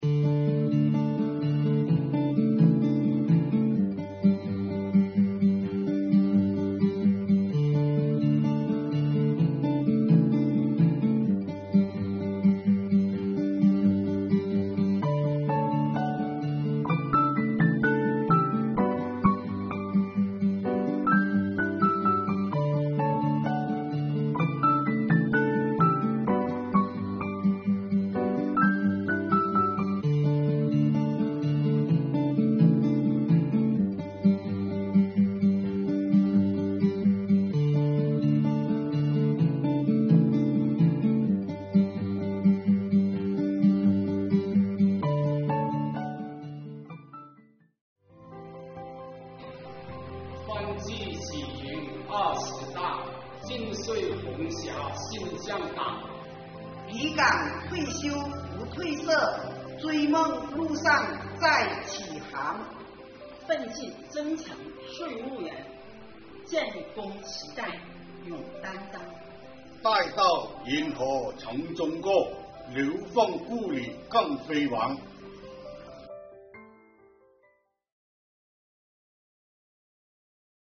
为弘扬中华民族尊老、敬老、爱老的传统美德，近日，在重阳节来临之际，钦州市税务局召开2022年重阳节座谈会，邀请市局机关退休党员干部到场共庆佳节，共话发展，喜迎党的二十大胜利召开。
座谈会气氛热烈欢快，老干部们对市局党委一直以来给予老同志的关心、关爱、关怀表示衷心感谢，对全系统上下奋进拼搏取得的突出成绩由衷点赞，并积极提出了改进工作的意见建议。